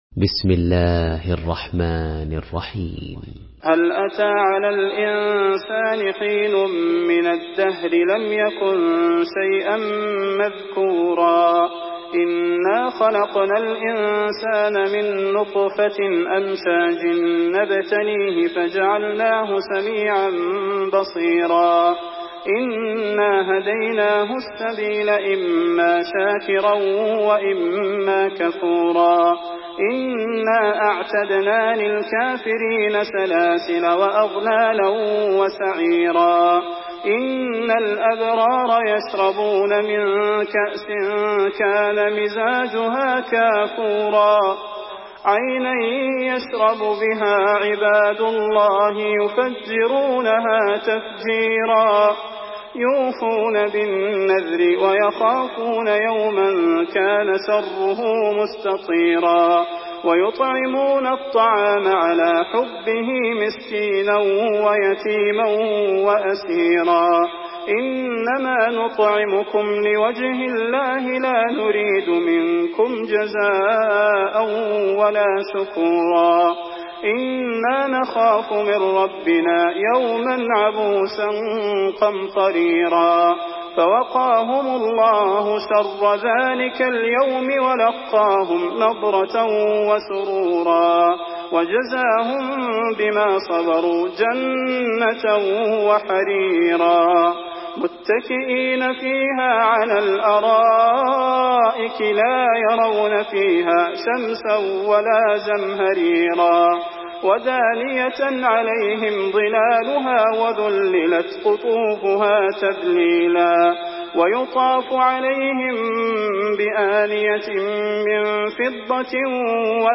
Surah Al-Insan MP3 in the Voice of Salah Al Budair in Hafs Narration
Murattal Hafs An Asim